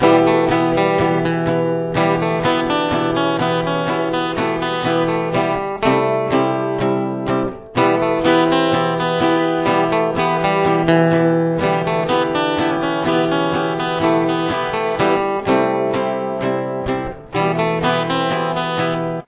There are two guitar parts: lead and rhythm.
Verse
Here is what both parts sound like together :